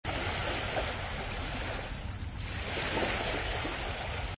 Wind
music_wind_waves.Ceeo08Cm.mp3